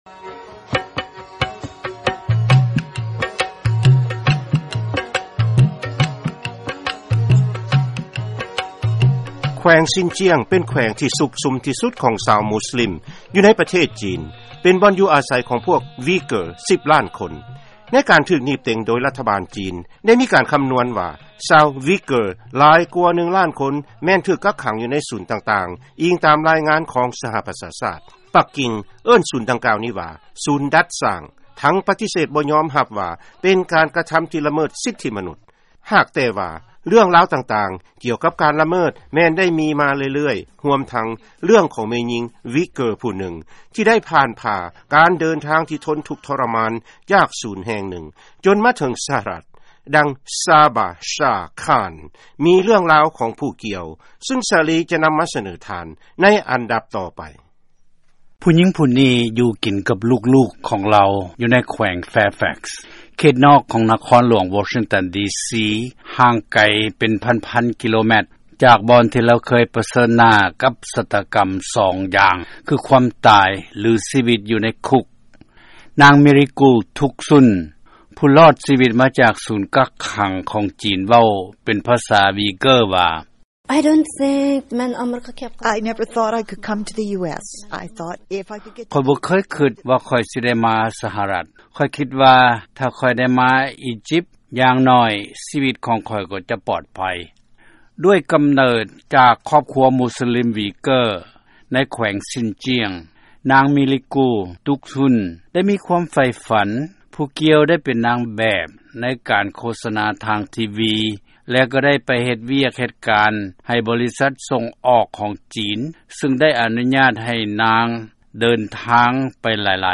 ເຊີນຟັງລາຍງານກ່ຽວກັບຊາວມຸສລິມຫວີເກີ້ໃນປະເທດຈີນ